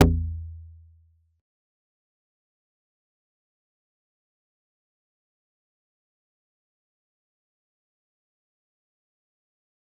G_Kalimba-A1-pp.wav